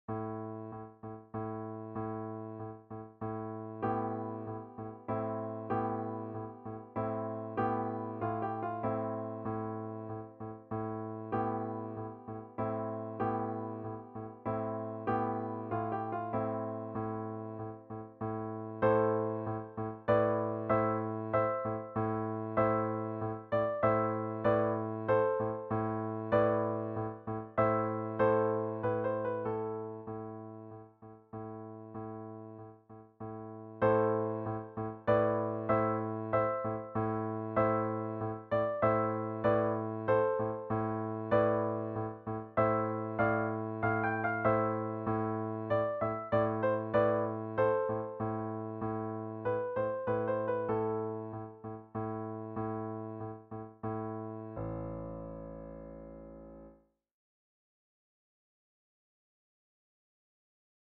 Arabian Dance for Easy/Level 3 Piano Solo
arabian-dance-piano.mp3